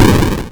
explode_c.wav